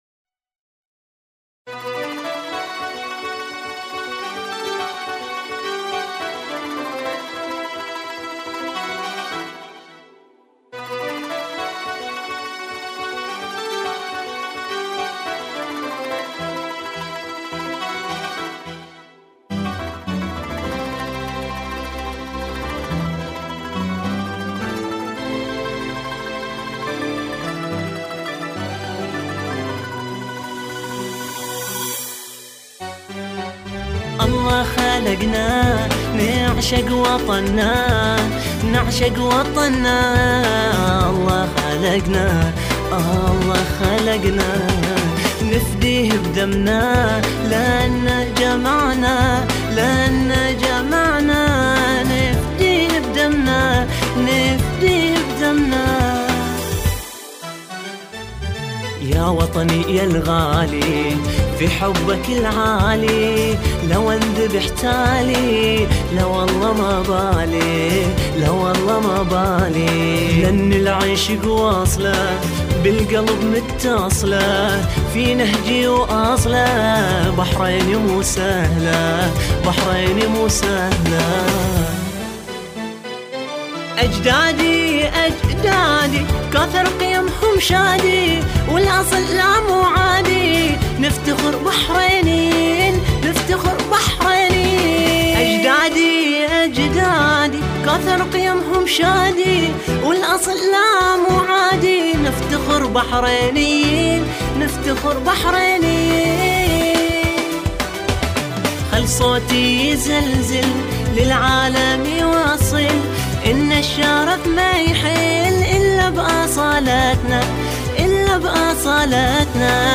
نشيد
أناشيد بحرينية